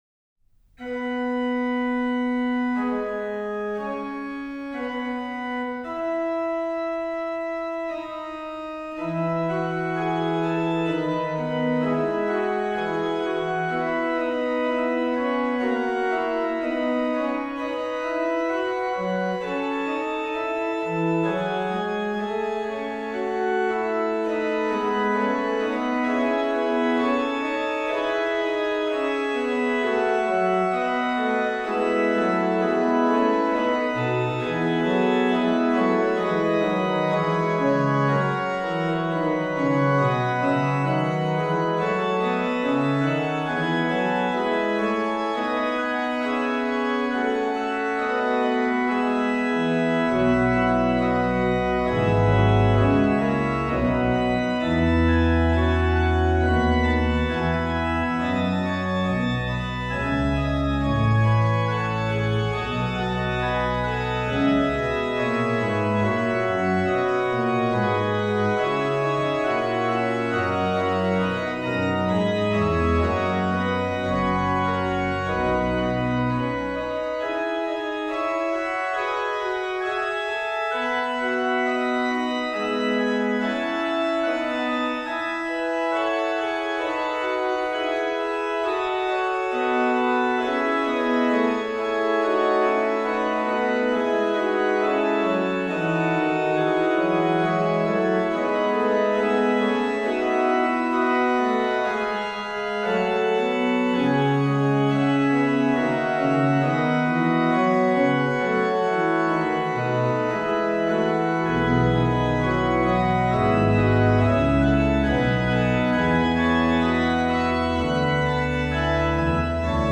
Registration   HW: Pr8, Viol8, Por8, Oct4, Qnt3, Oct2
Ped: Pr16, Sub16, Viol16, Qnt6, Oct8, Oct4
HW: +Por16, Qnt16, Mix, Tr8